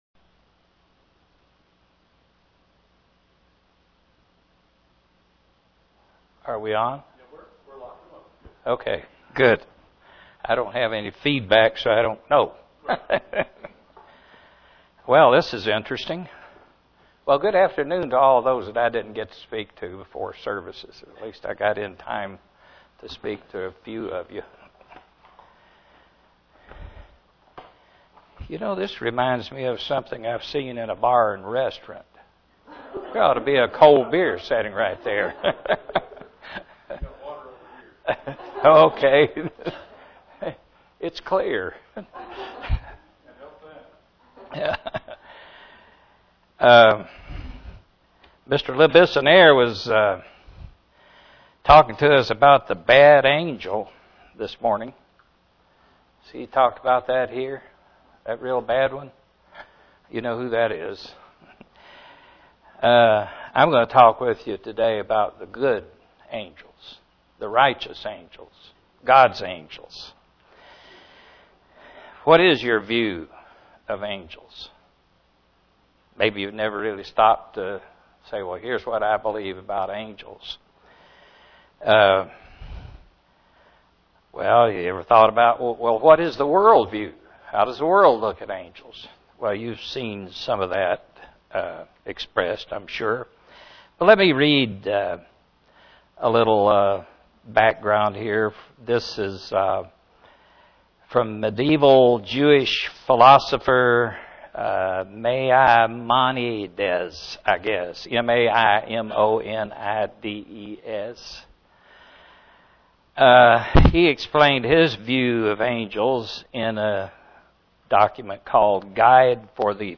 (Presented to the Knoxville TN, Church)